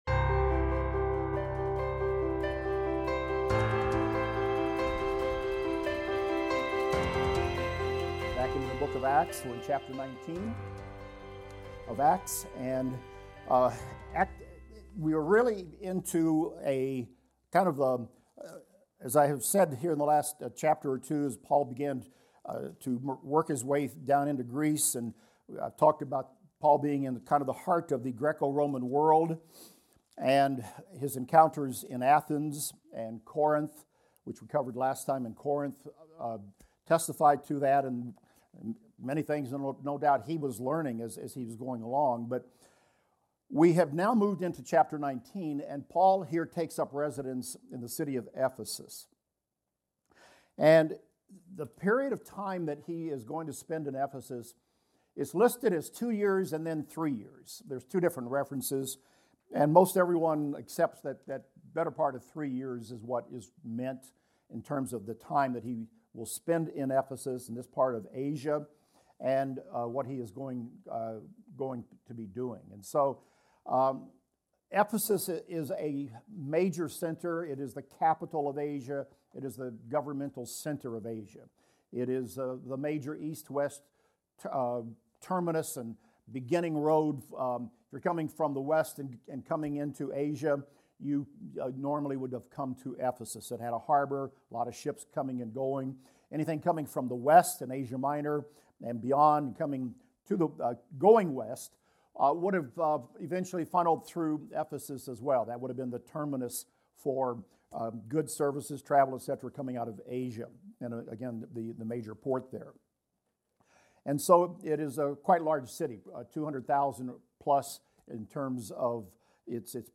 In this class, we will discuss Acts 19:6-16 and examine the following: Paul laid his hands on some disciples in Ephesus, and they received the Holy Spirit, spoke in tongues and prophesied. Some Jewish men who practiced exorcism attempted to invoke the name of Jesus, but the evil spirit recognized their lack of authority and attacked them.